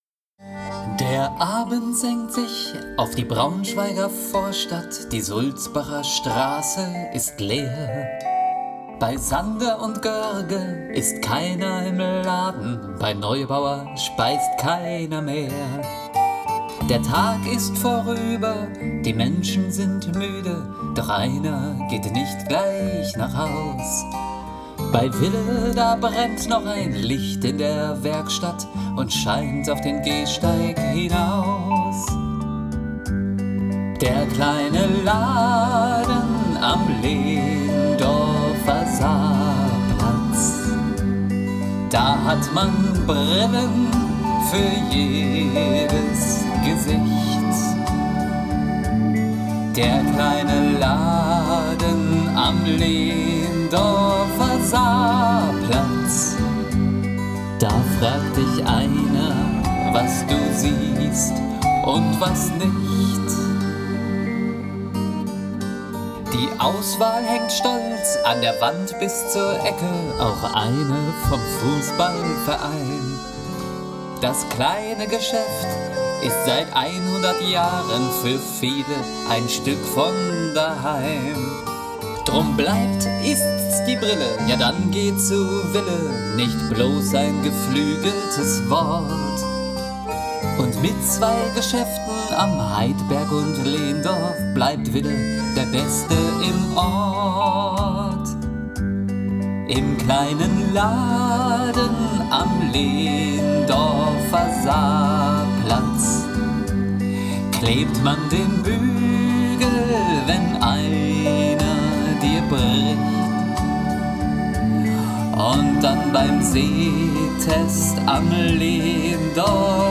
Mit viel Herz, Humor und einer Prise Nostalgie haben die beiden eine musikalische Hommage an unser Unternehmen kreiert.